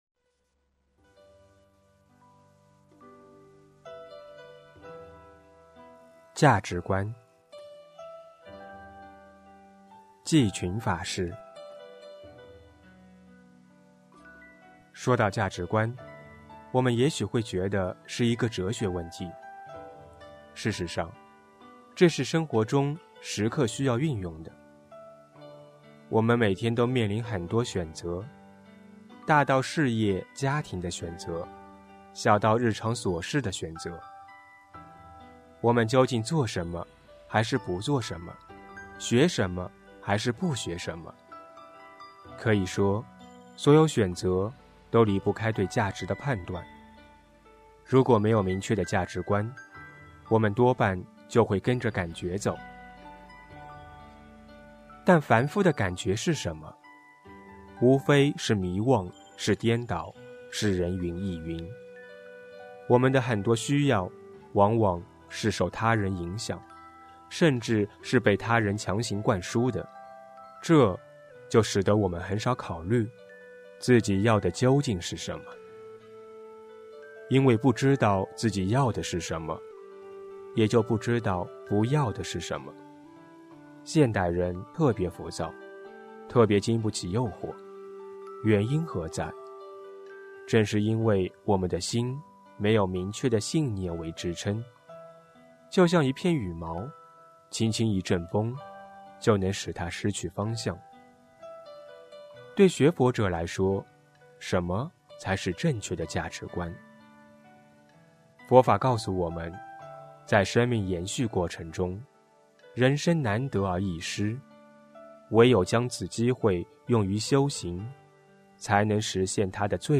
——济群法师法语系列 朗诵、配乐